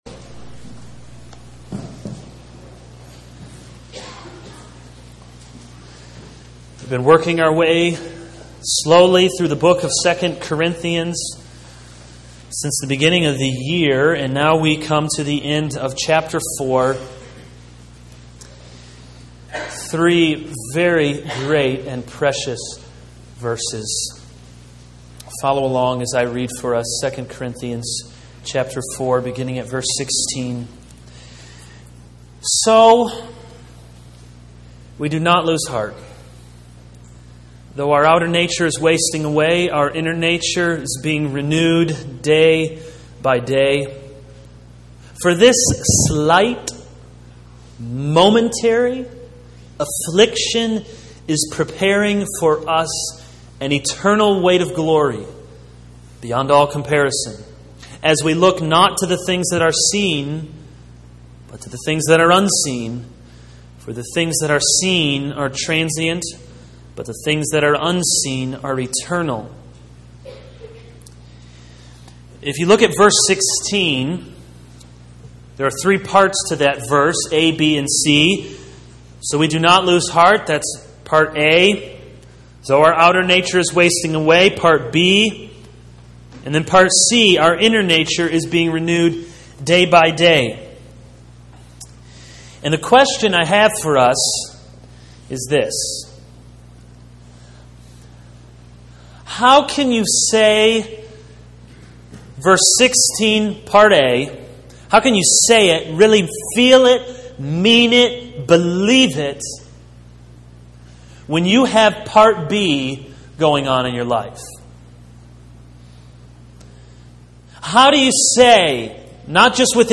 This is a sermon on 2 Corinthians 4:16-18.